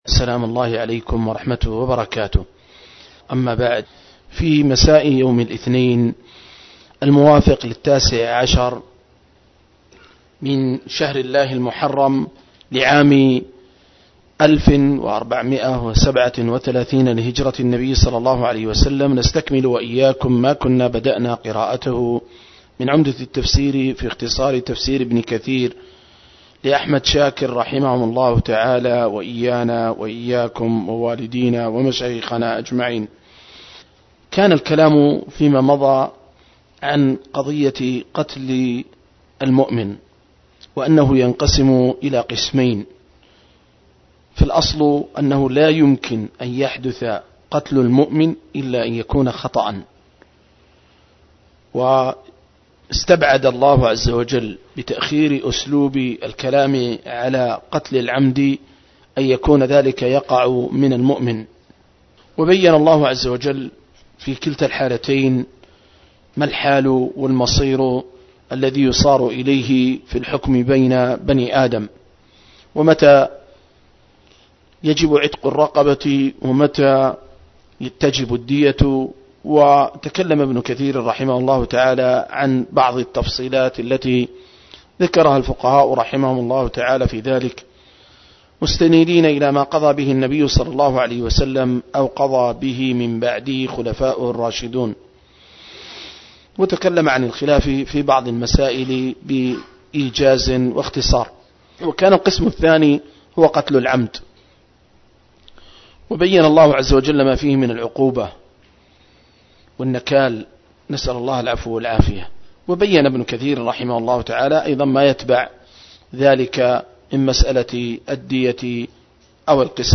097- عمدة التفسير عن الحافظ ابن كثير رحمه الله للعلامة أحمد شاكر رحمه الله – قراءة وتعليق –